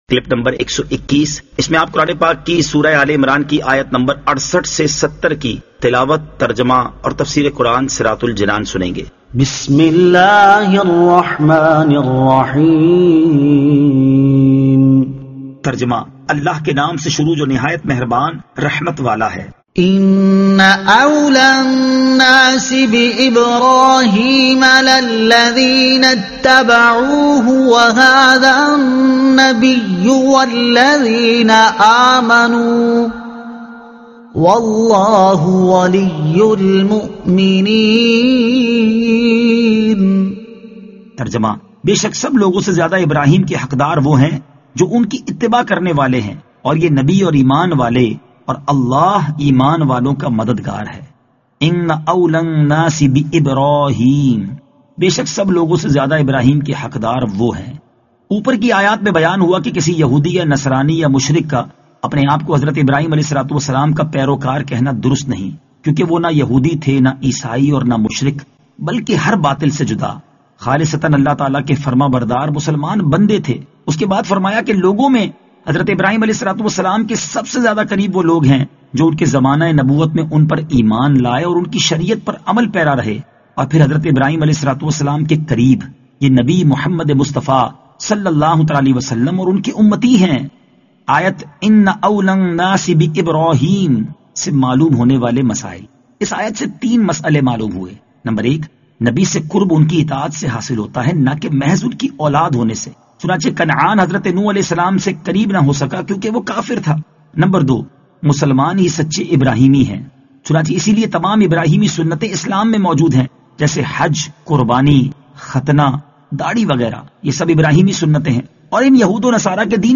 Surah Aal-e-Imran Ayat 68 To 70 Tilawat , Tarjuma , Tafseer